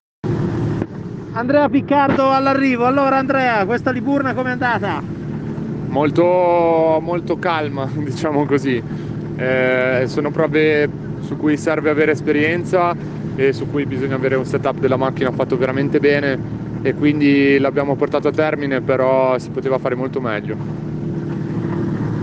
Interviste Rally Liburna Terra 2021
Sabato - Interviste finali